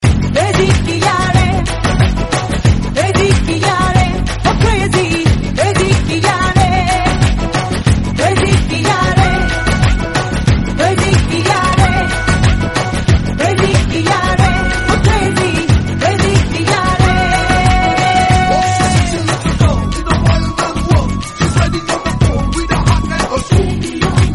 a superhit dance song with energetic beats and catchy vibes.